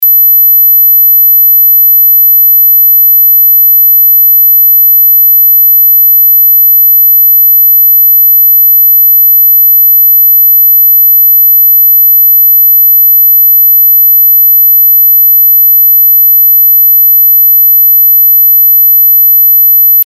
サイン波 12000Hz
/ F｜システム電子音 / F-04 ｜その他 電子音